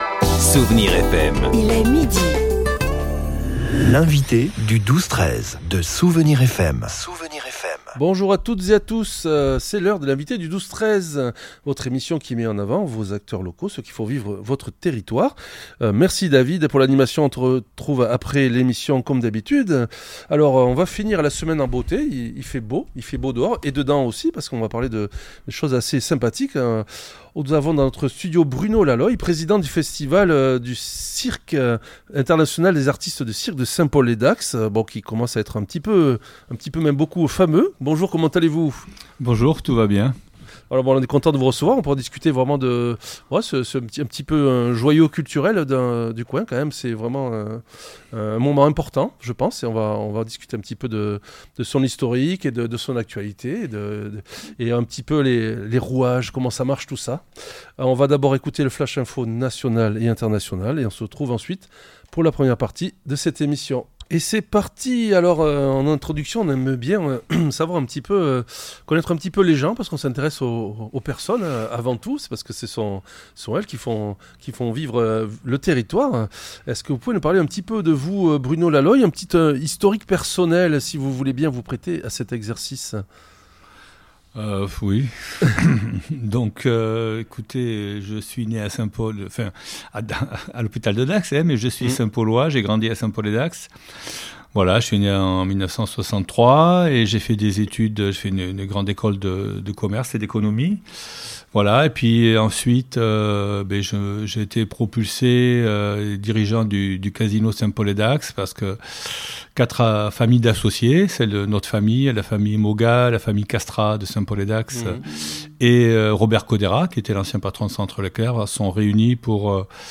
L’entretien a permis de revenir sur l'évolution du festival : d'une aventure lancée par 30 bénévoles en 1999 à une machine logistique impressionnante portée aujourd'hui par plus de 130 passionnés.